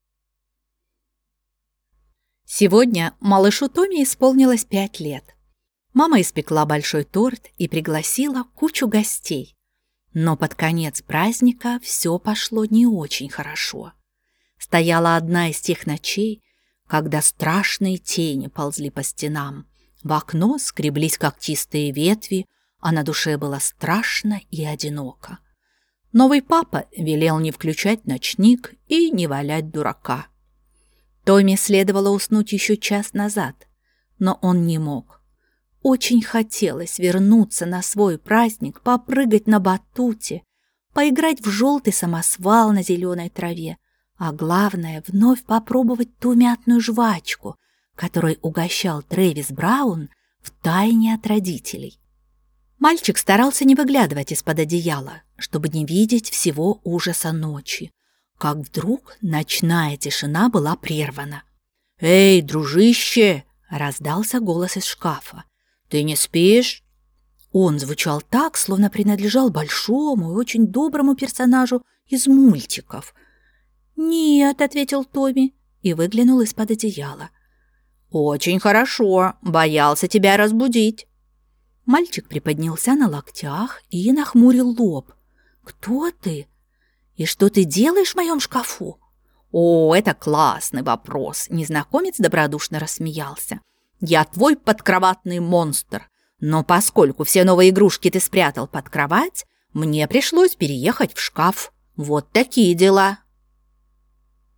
Запись с дикторами Rideró